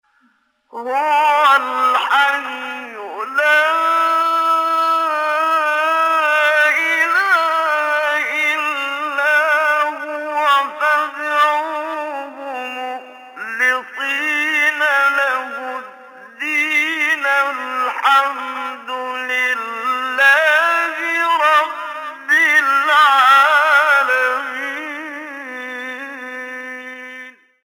سوره : غافر آیه: 65 استاد : محمد صدیق منشاوی مقام : بیات قبلی بعدی